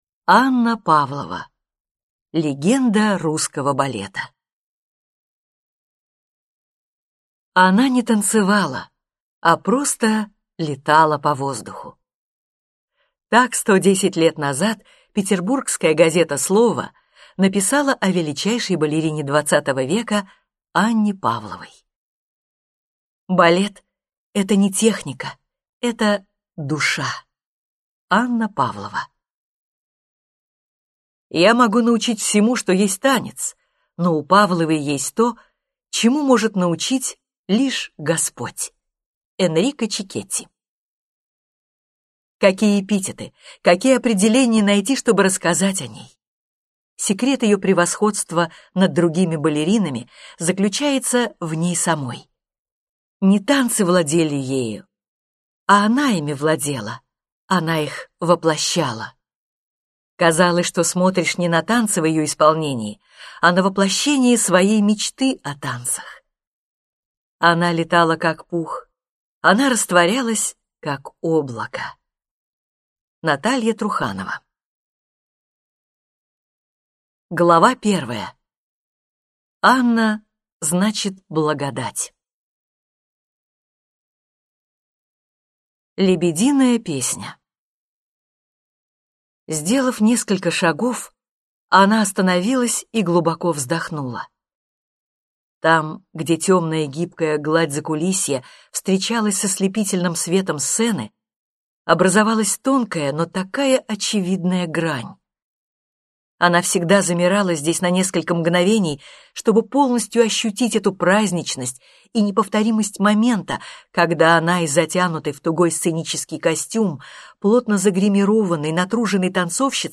Аудиокнига Анна Павлова. Легенда русского балета | Библиотека аудиокниг